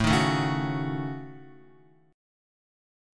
A6b5 Chord